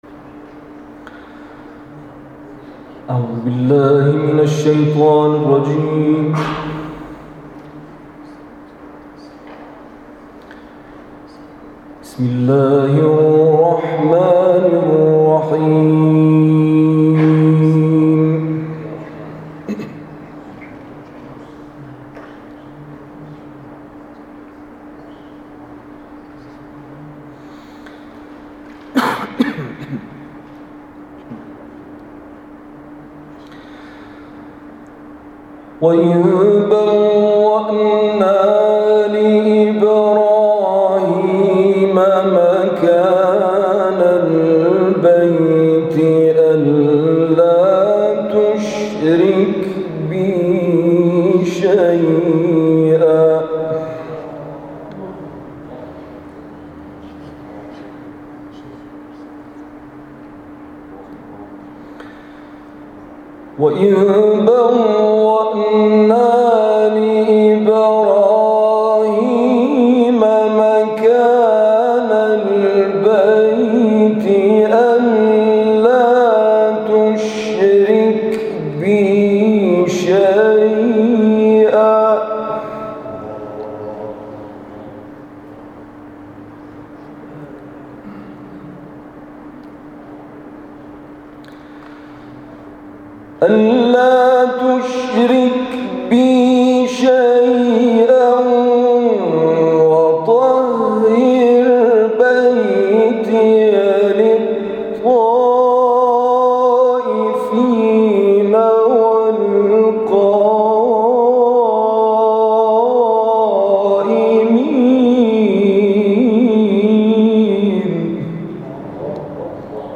تلاوت
در گرامیداشت شهدای قرآنی منا
در شورای عالی قرآن برگزار شد